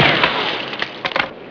Totally Free War Sound Effects MP3 Downloads
Machinegun3.mp3